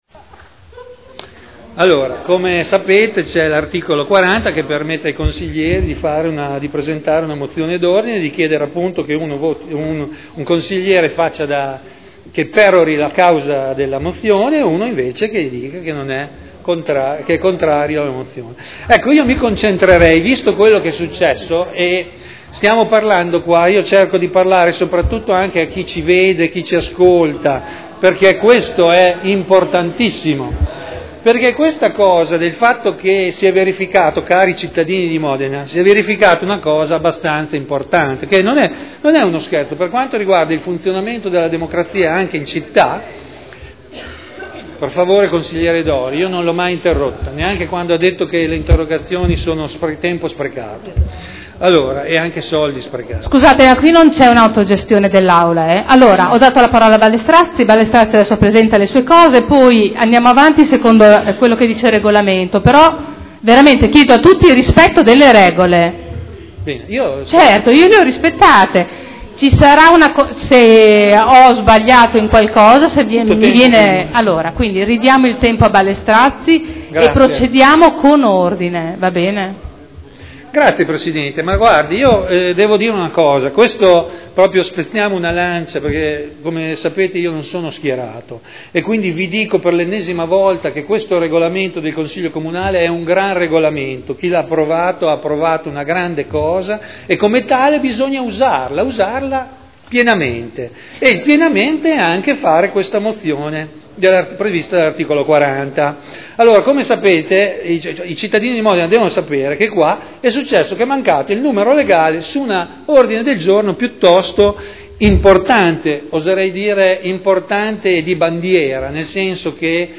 Mozione d'ordine.